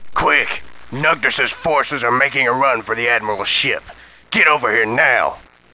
Human Male, Age 35
Blaze is a tough talking no nonsense Colonel with an affinity to get things done fast and correctly.